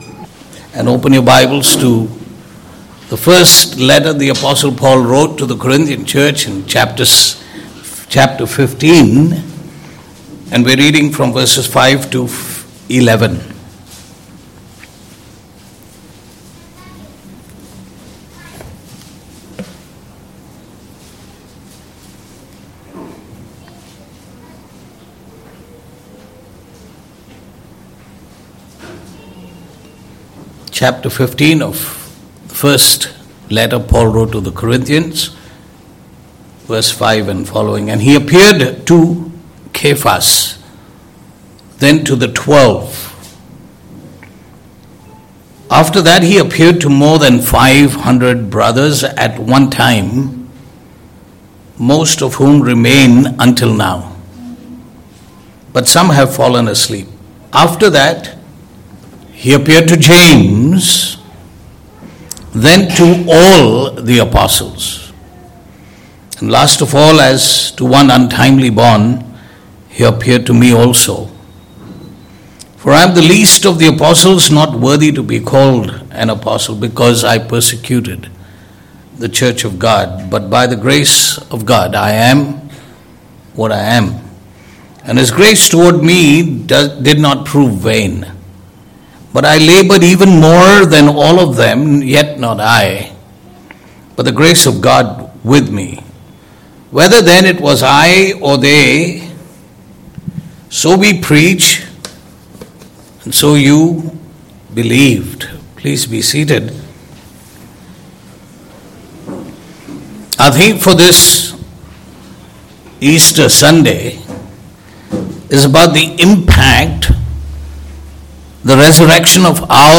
Easter-Sermon1.mp3